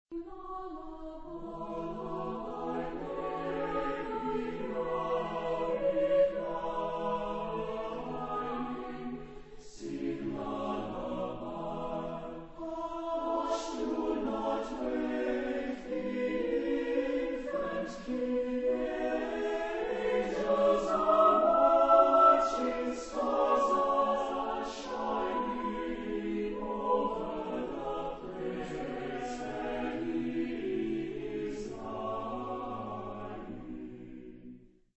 Genre-Style-Forme : Sacré ; Chant de Noël ; Berceuse
Type de choeur : SATB  (4 voix mixtes )
Tonalité : mi majeur
Origine : Pays Basque